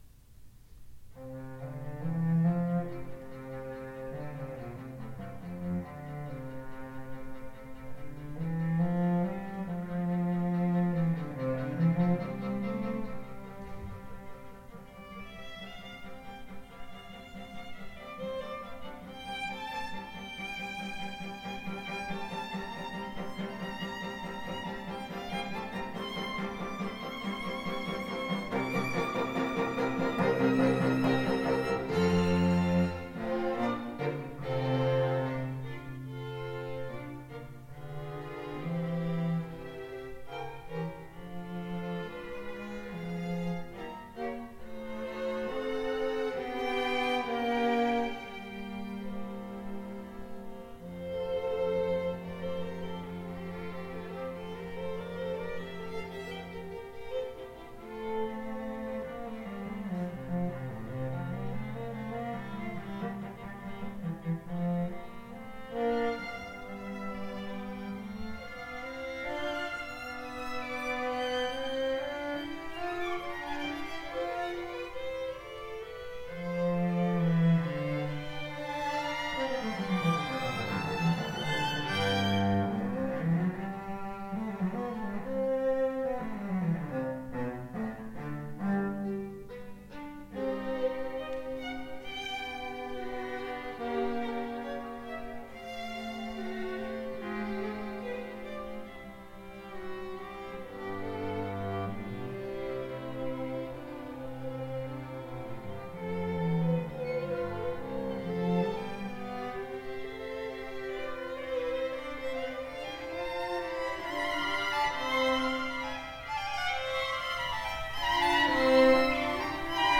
2:00 PM on August 13, 2017, St. Mary Magdalene
Ravel Quartet in F major
Assef vif